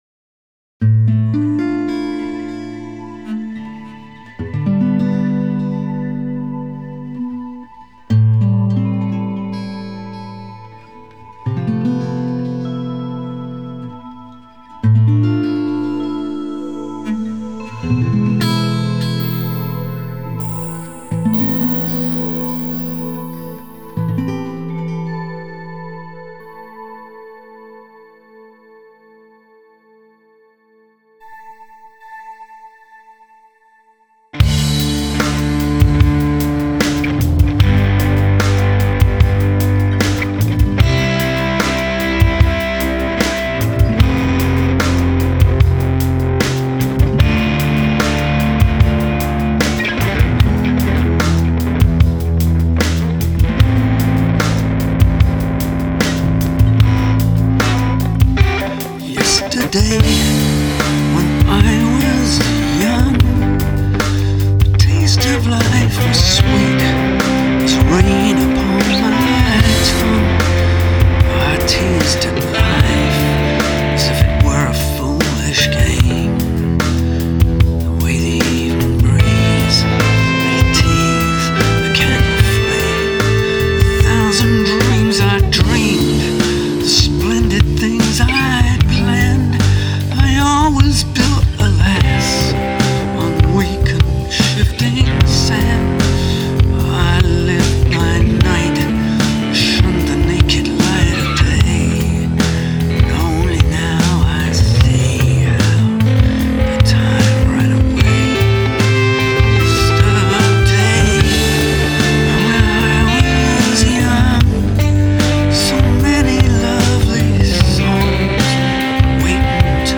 I realised that it needed a much more emotional approach.
And, of course, xylophones and clarinets.